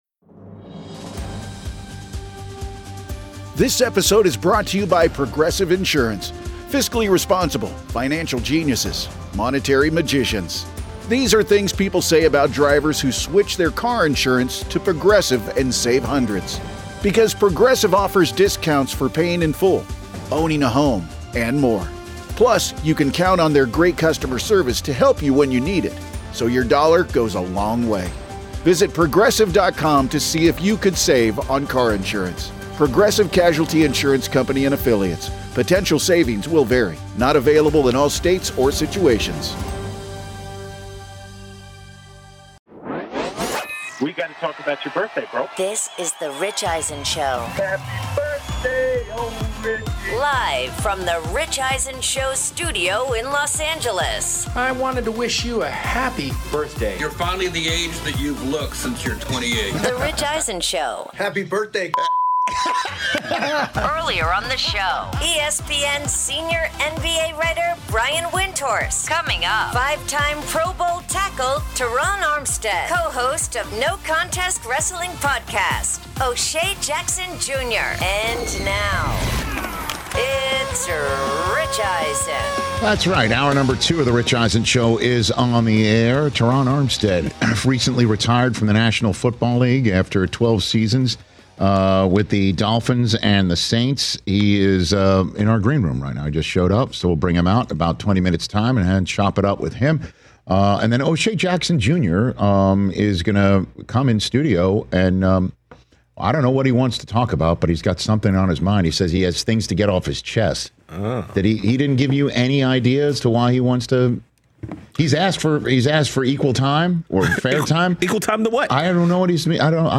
6/24/25 - Hour 2 Rich reacts to Aaron Rodgers telling Pat McAfee that this season with the Pittsburgh Steelers will most likely be his last in the NFL. Former All-Pro NFL OL Terron Armstead joins Rich in-studio to discuss his decision to retire after a career that saw him go to 5 Pro Bowls, says how Dolphins QB Tua Tagovailoa compares with future Hall of Famer Drew Brees, explains why Rams DE Jared Verse will have a great career rushing the passer, and explains how close Miami is to being a legit contender, and more.